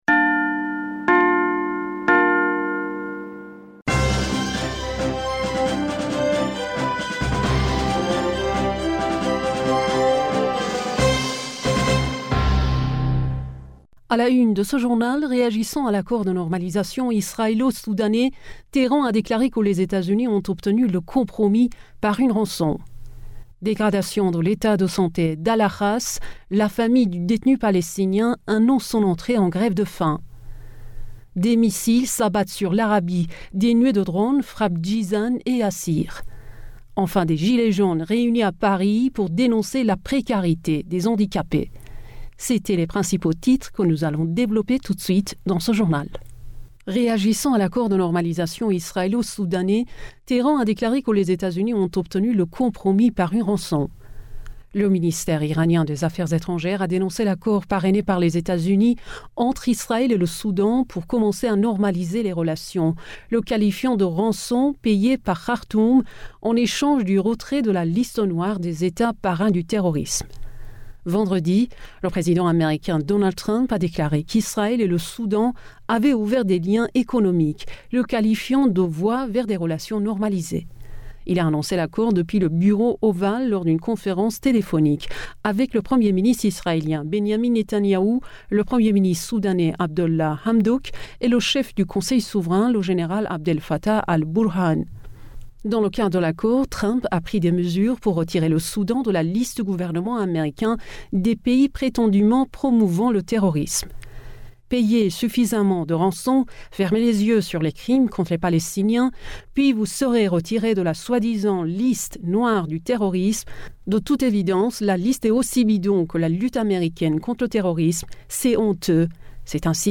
Bulletin d'informationd u 25 Octobre 2020